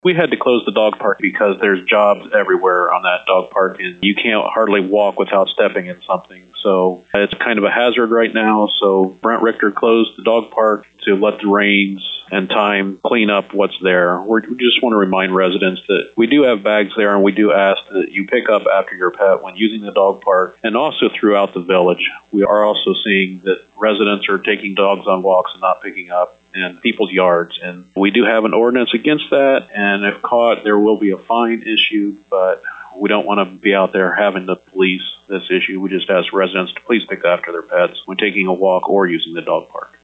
To hear comments from New Bremen Mayor Bob Parker: